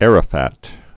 (ărə-făt, ärə-fät), Yasir also Yasser Originally Mohammed Abdel-Raouf Arafat (As Qudwa al-Hussaeini). 1929-2004.